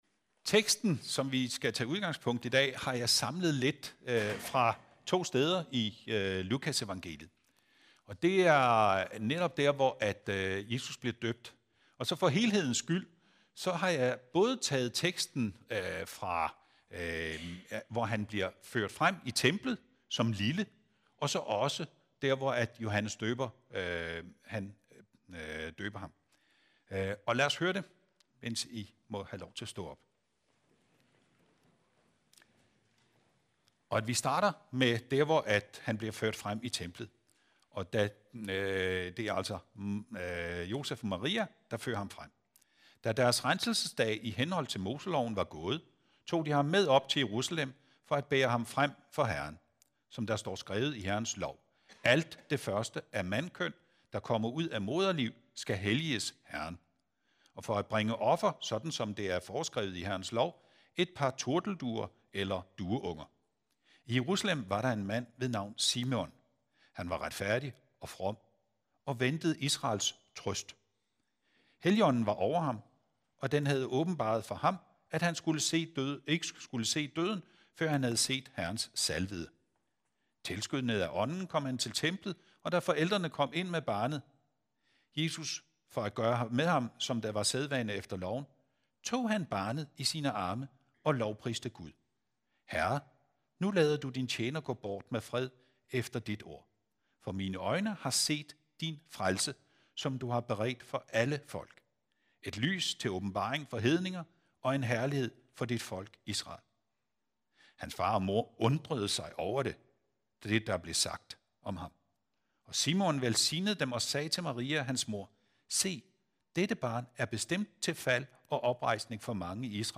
Metodistkirken i Odense.